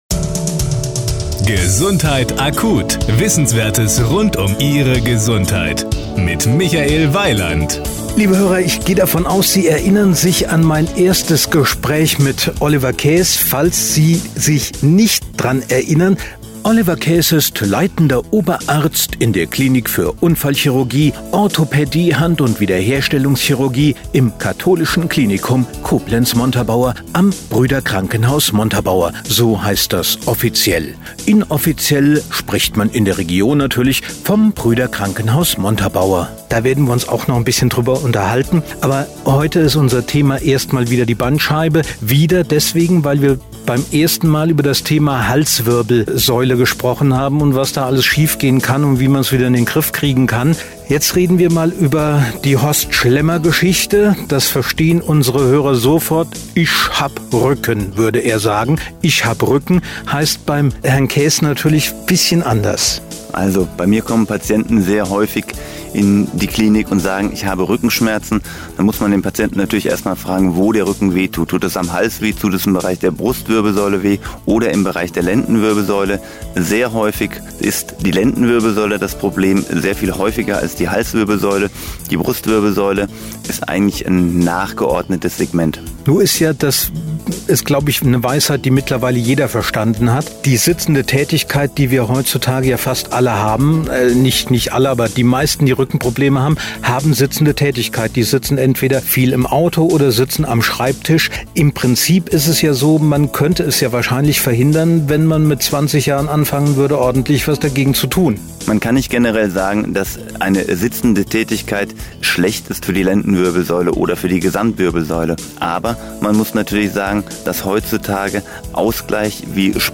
Sie sind hier: Start » Interviews » Interviews 2012